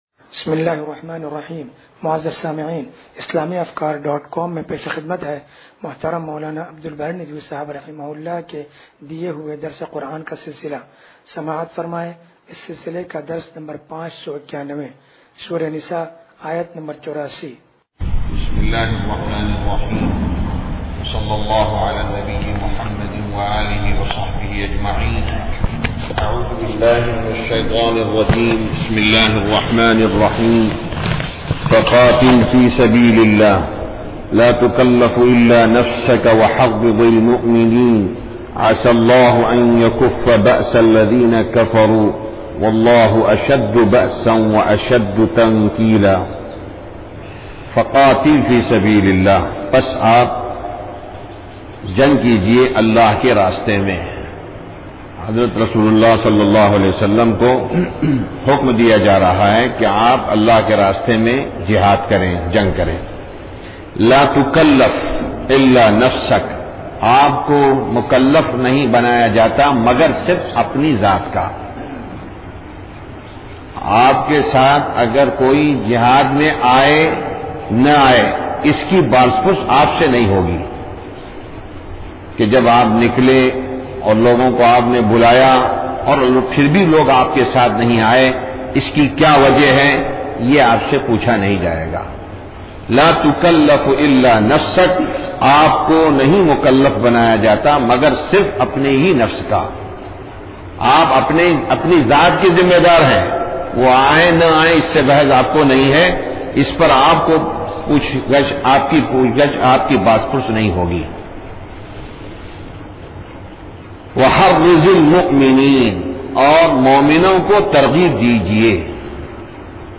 درس قرآن نمبر 0591